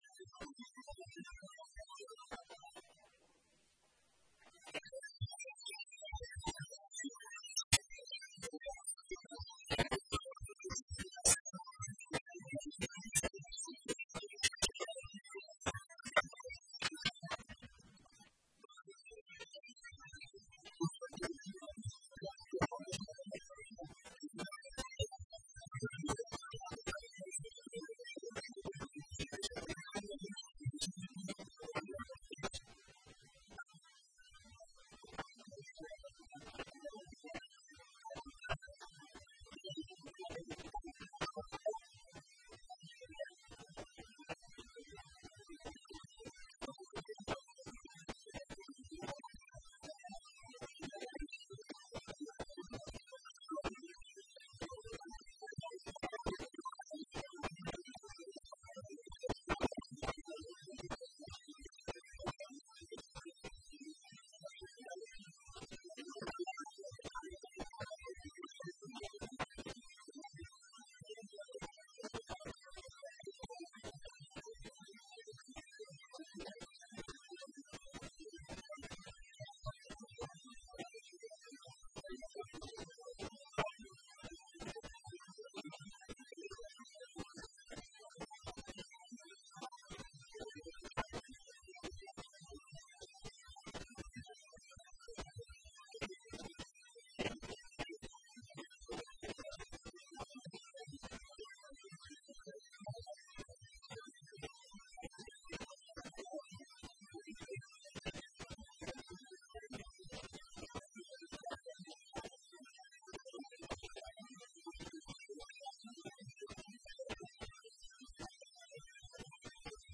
El Intendente Dr. Eduardo "Bali" Bucca hablo sobre Viviendas :: Radio Federal Bolívar
Desde el Predio donde se Construyen 100 en estos momentos